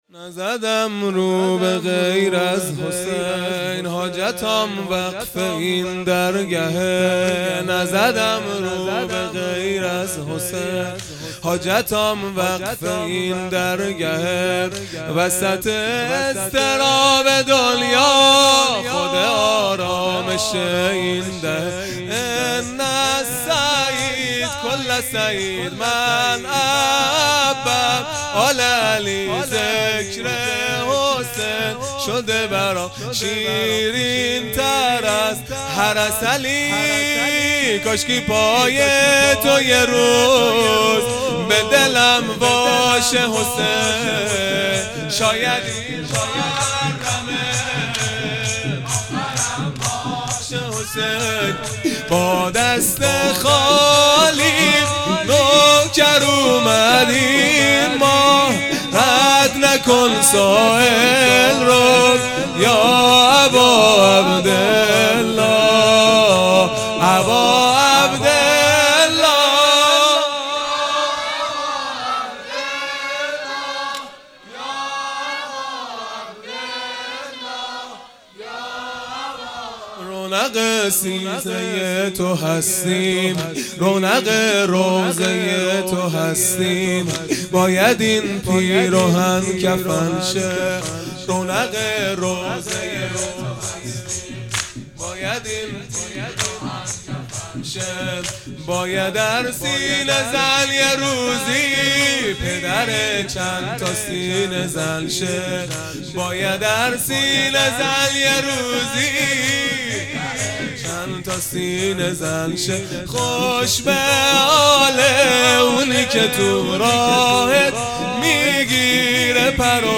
سرود پایانی | نزدم رو به غیر از حسین | شنبه ۲۳ مرداد ۱۴۰۰
دهه اول محرم الحرام ۱۴۴۳ | شب ششم | شنبه ۲۳ مرداد ۱۴۰۰